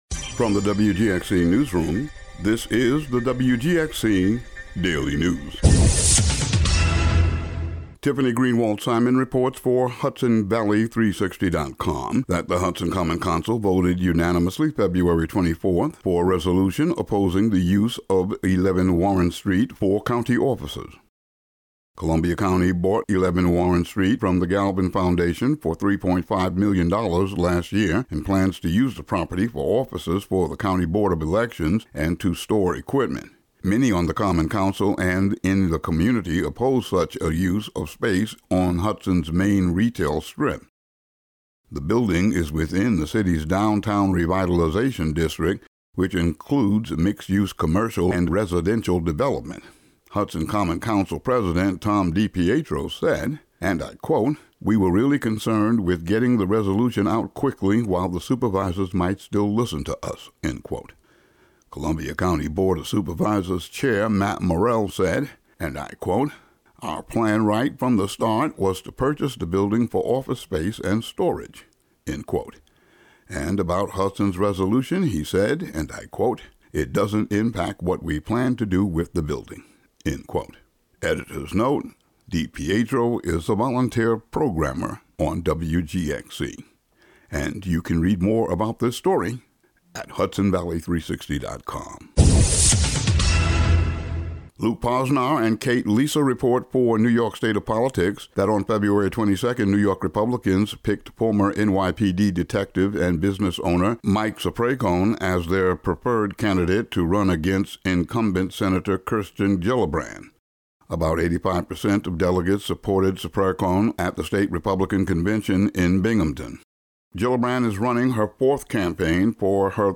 Today's audio daily news update.
Today's daily local audio news.